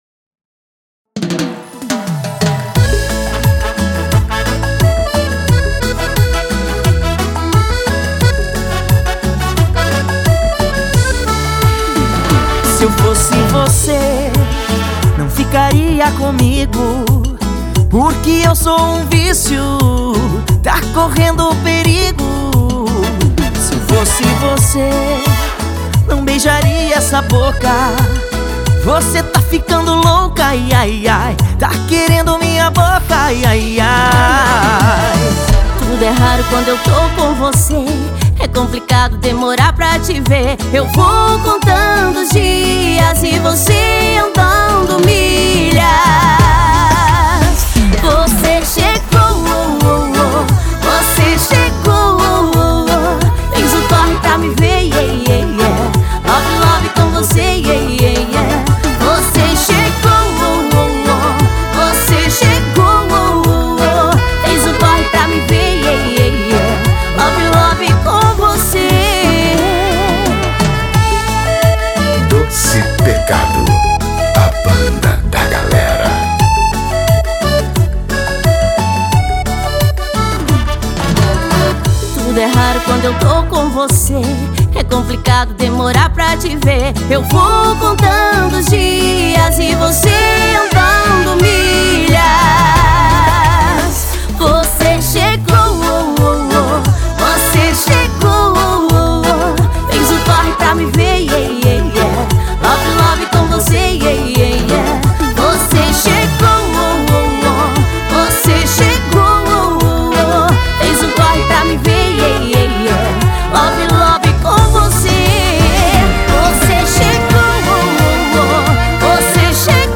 Vocalista
Teclados e Gaita
Baixista e Vocal
Baterista
Guitarrista e Vocal